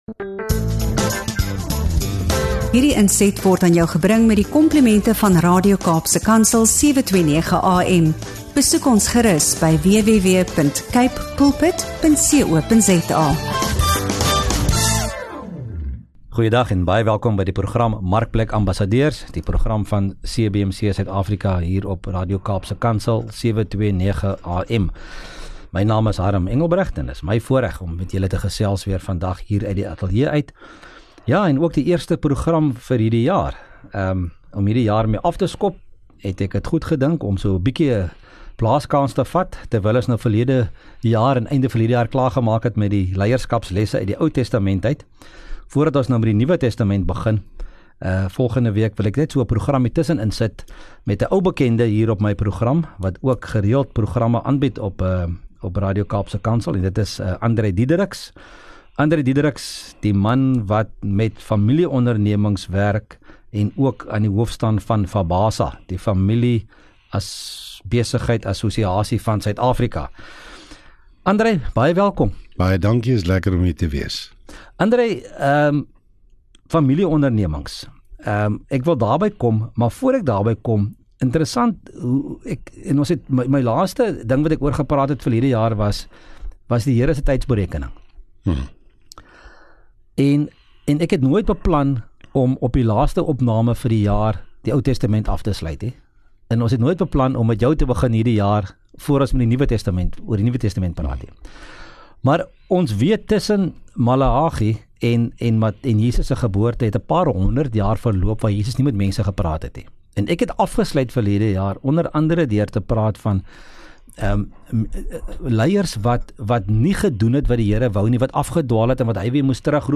4 Jan MARKPLEK AMBASSADEURS (CBMC) - 'n Geselskap